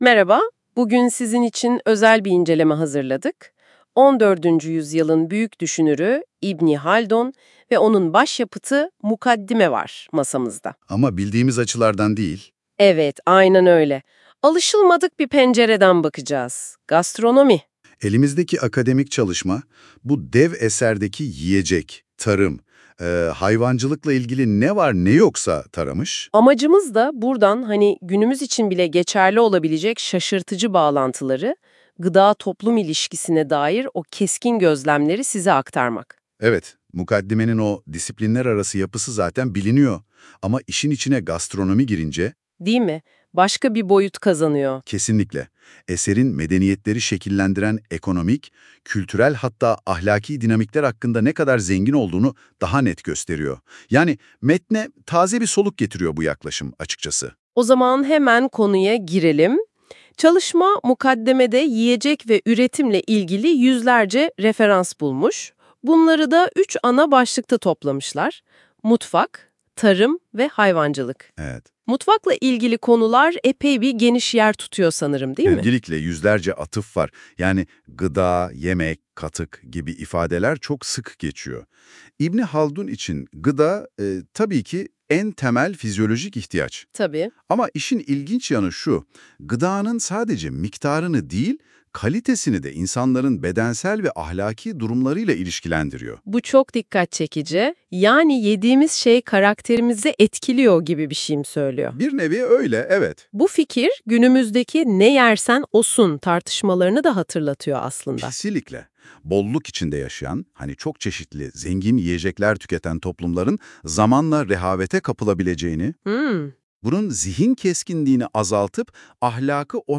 İbn Haldun’un Mukaddimesi ve Gastronomi Üzerine Söyleşi